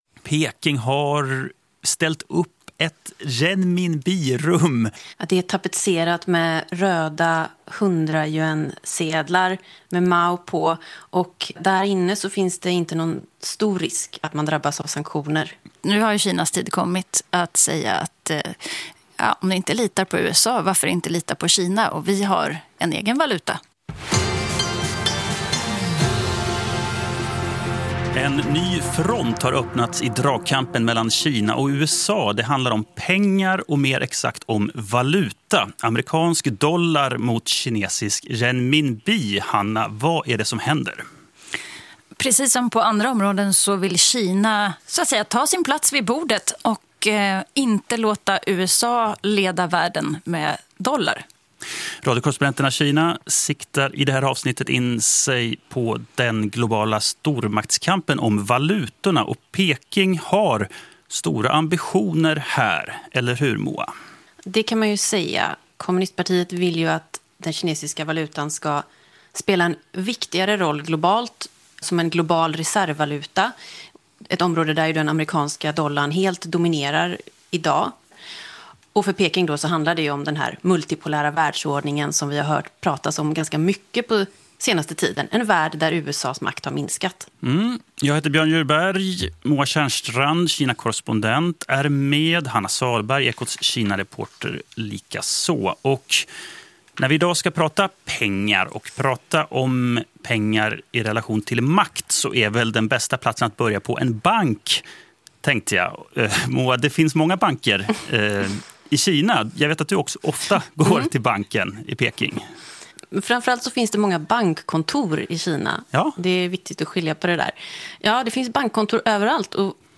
Ljudklipp: Bloomberg, DWS news (Youtube).